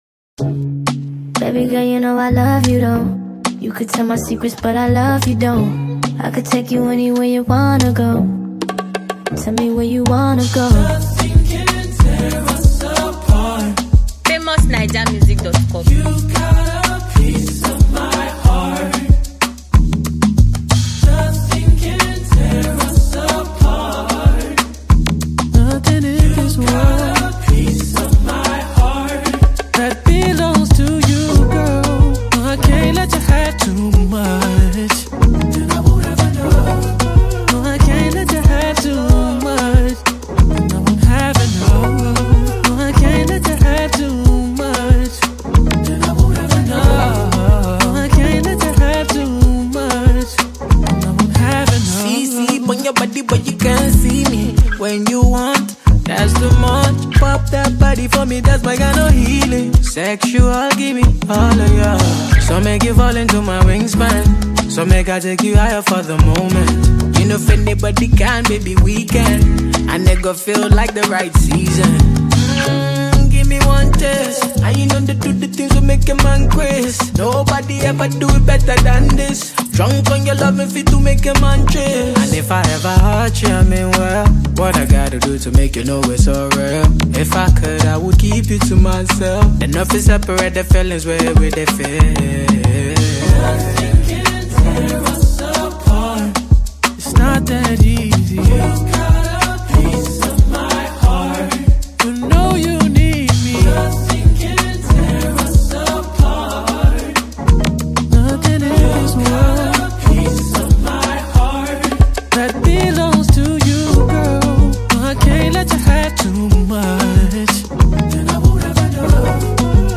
Nigerian vocalist musician and skilled craftsman